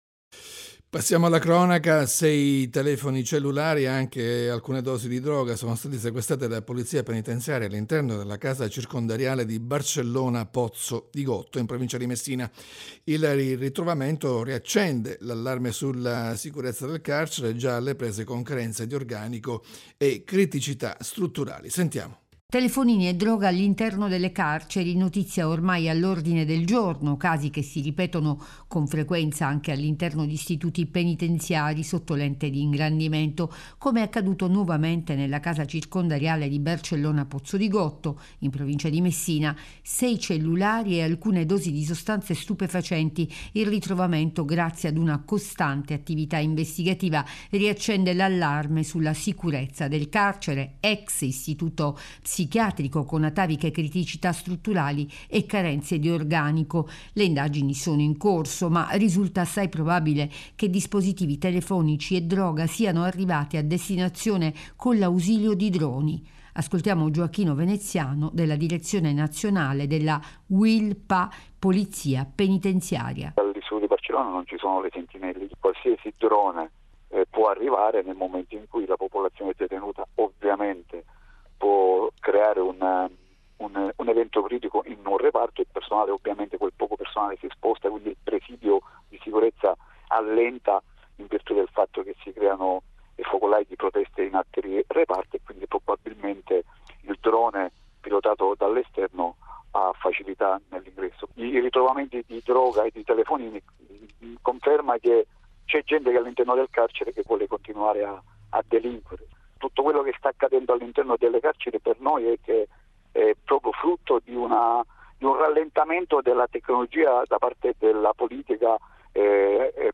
in diretta a Giornale Rai Sicilia